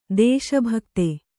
♪ dēśa bhakte